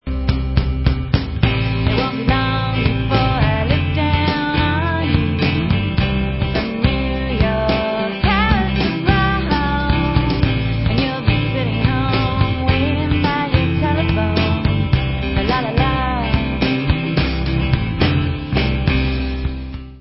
sledovat novinky v oddělení Alternativní hudba